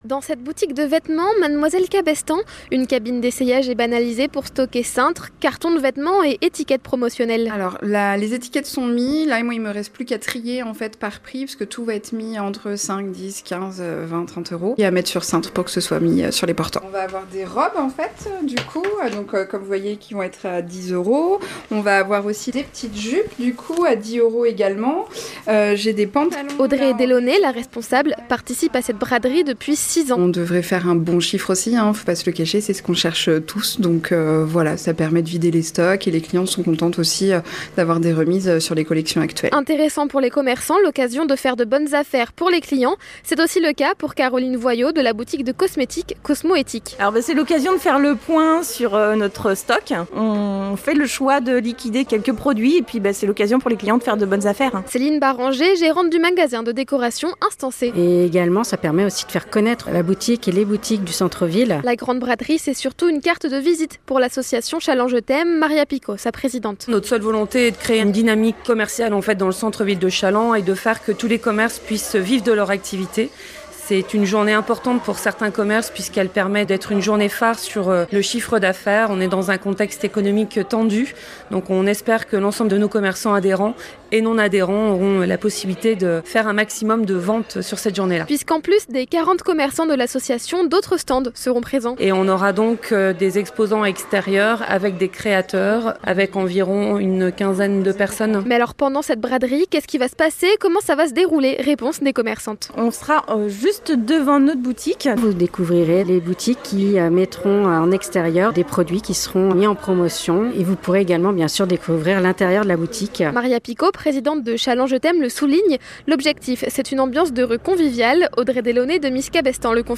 Podcast reportages